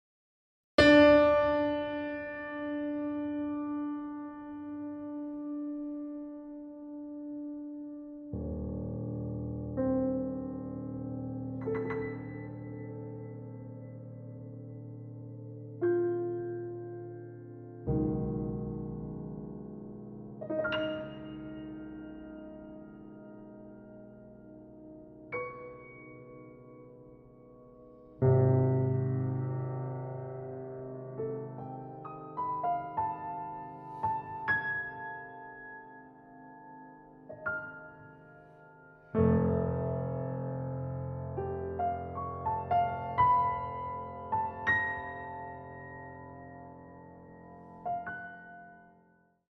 • Genres: Solo Piano, Classical